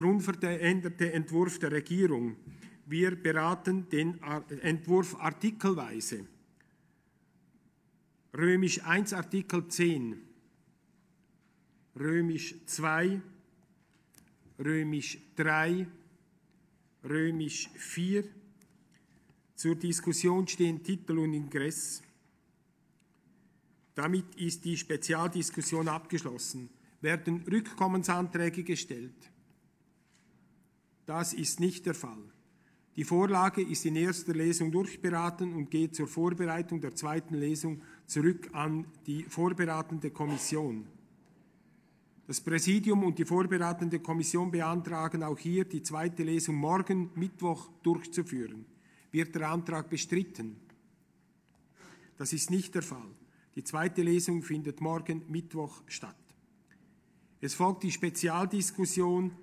Session des Kantonsrates vom 18. bis 20. Mai 2020, Aufräumsession